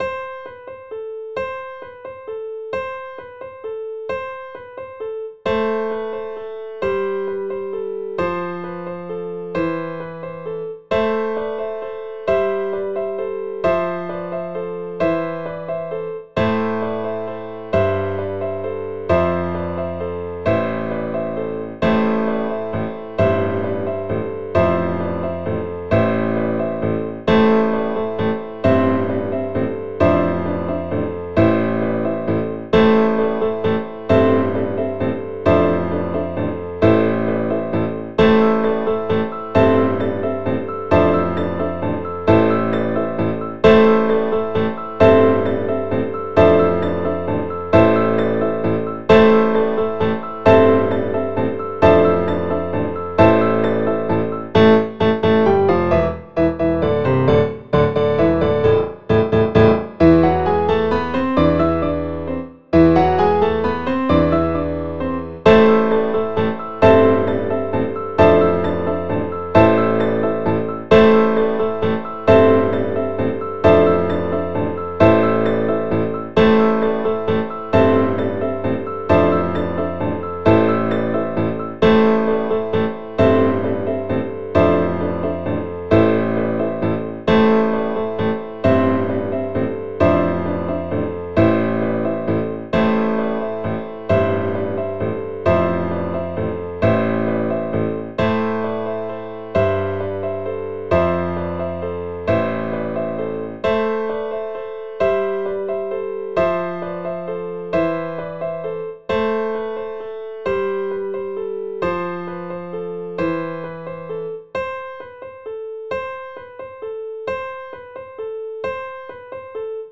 quick and easy piano ensembles for small to large groups